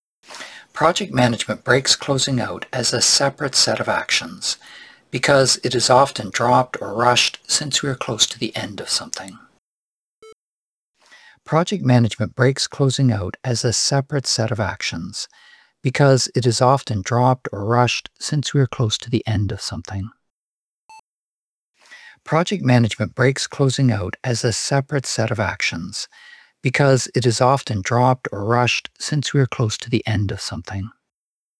It was recorded in a room with bare walls, and sounds quite tinny to me, high pitched and metallic.
AI services like Adobe enhance (currently freemium) remove the worst offenders, (noise, reverb)
But IMO the AI output benefits from finessing, like EQ, de-essing, and de-clicking …